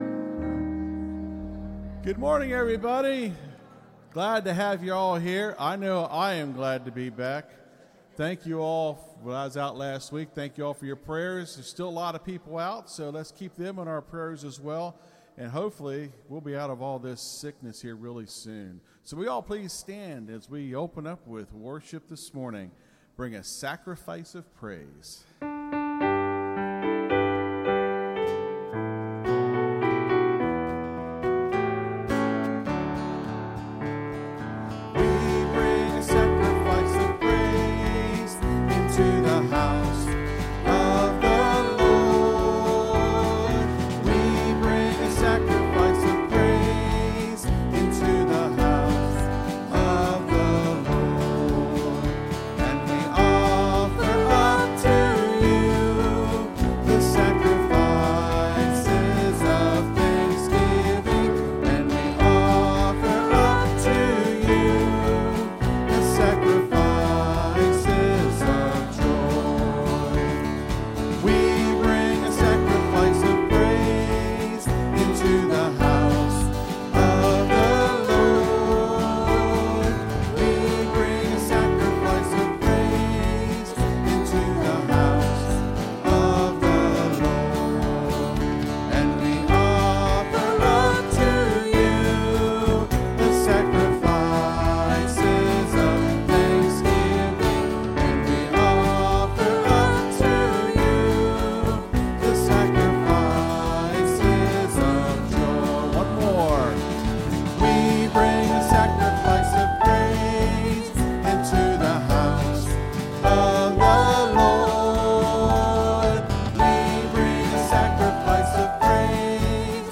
(Sermon starts at 23:00 in the recording).